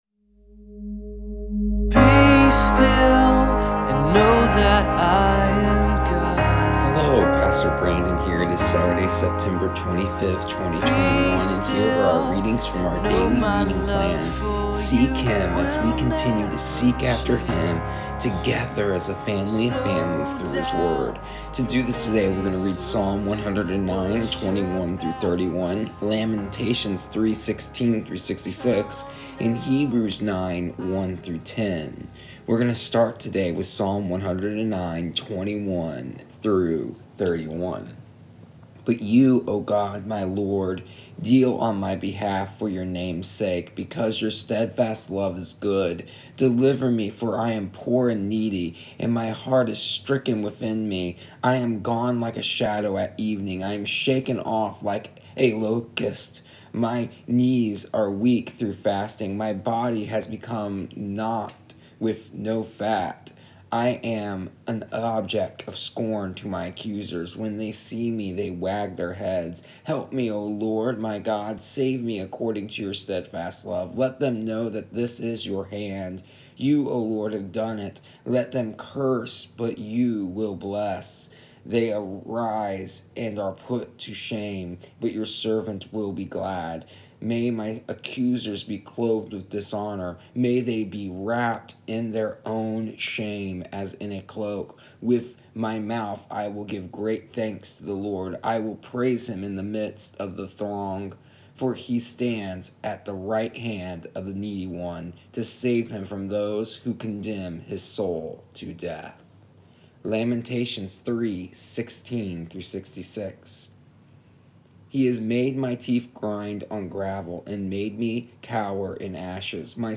Here is the audio version of our daily readings from our daily reading plan Seek Him for September 25th, 2021. Today we learn in all lamenting there is a need for hope.